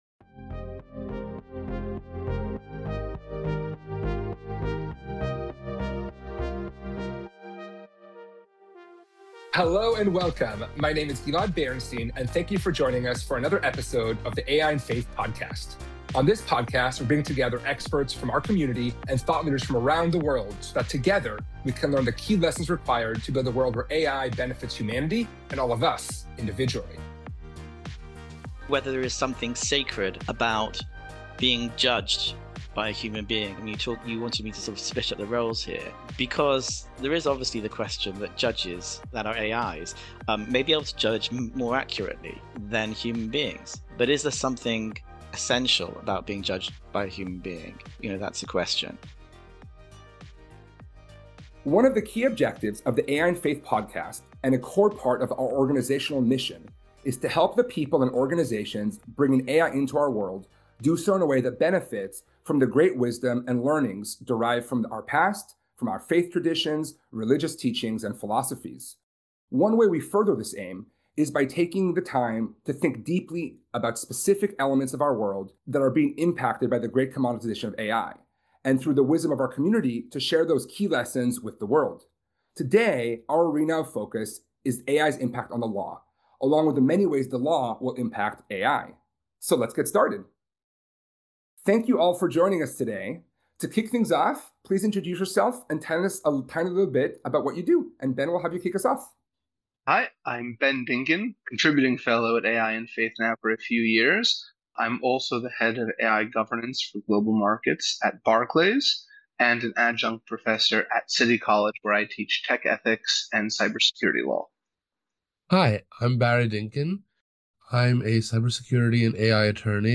In this conversation, we were joined by three legal experts to discuss the myriad of ways that artificial intelligence is impacting the law, our legal system, and our lives. We explore this topic from three different overlapping angles: AI’s impact on the practice of law; AI impact on key legal principles and human rights; and the law’s impact on the development of AI.